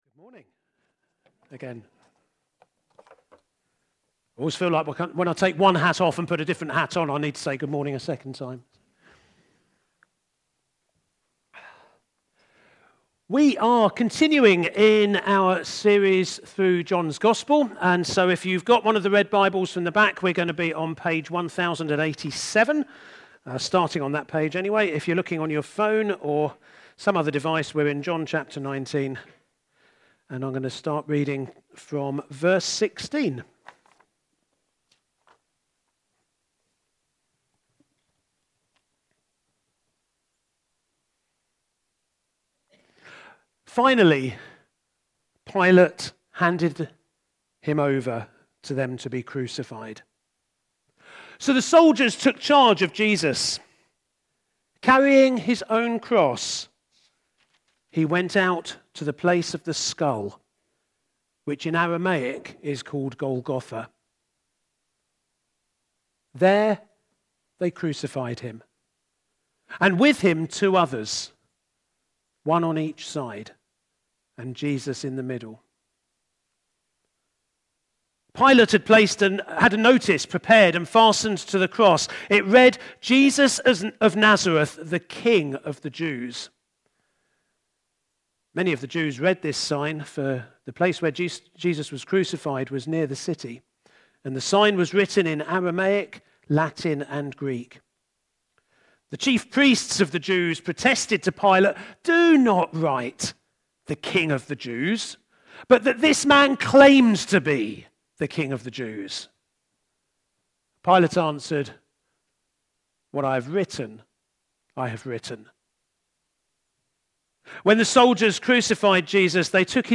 8th-June-2025-At-the-foot-of-the-cross-Sermon.mp3